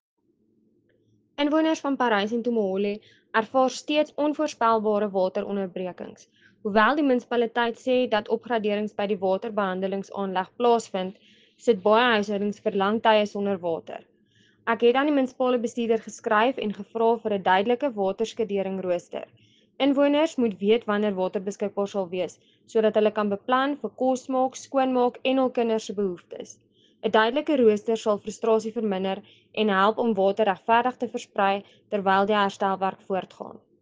Afrikaans soundbites by Cllr Marié la Cock and